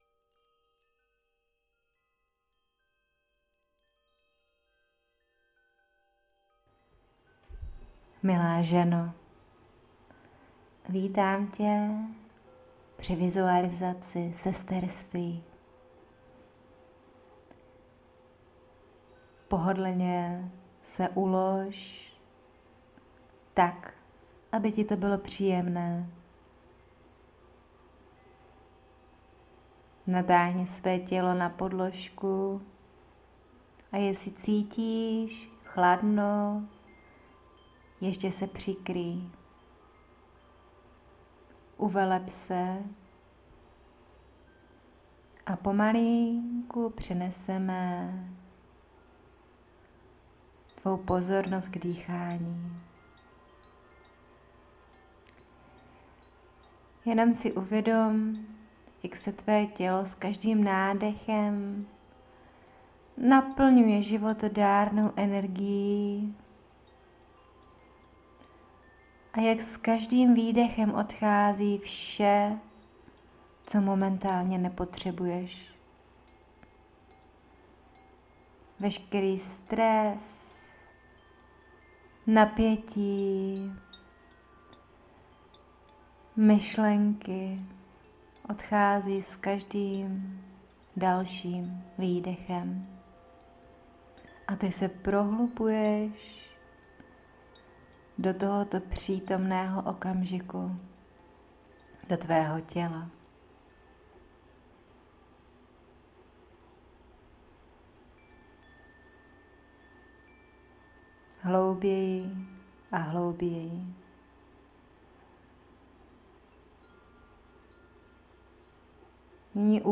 Meditace Sesterství